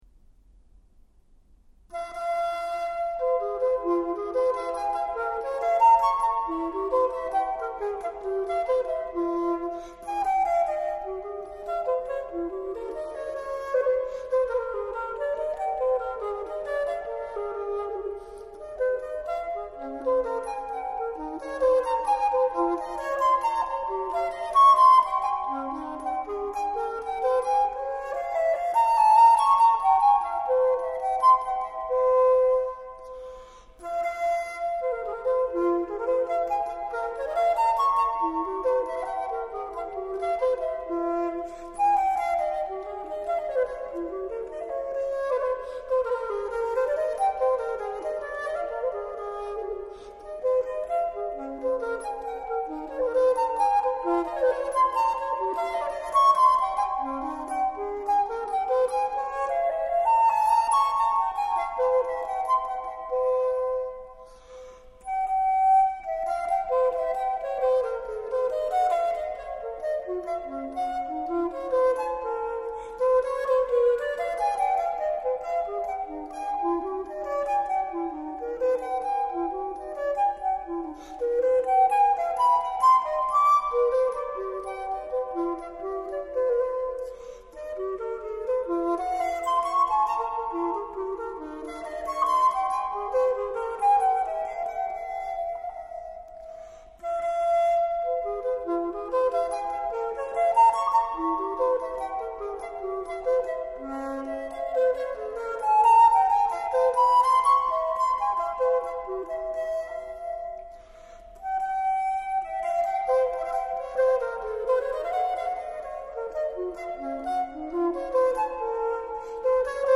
flûte traversière